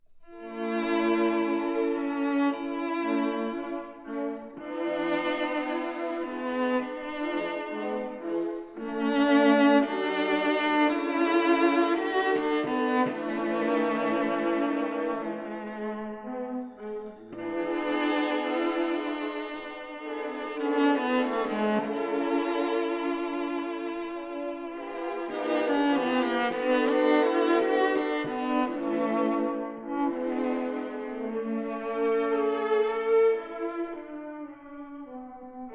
A 1960 stereo recording